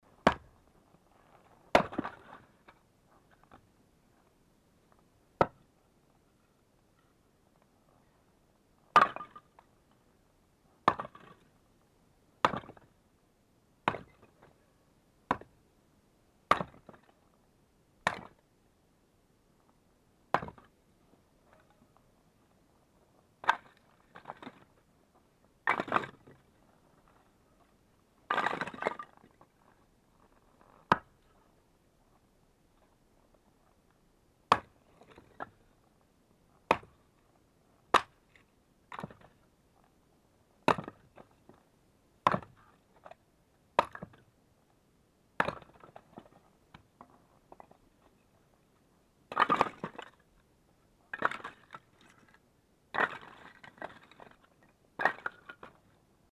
Звук рубки дров на зиму